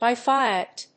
アクセントby fíat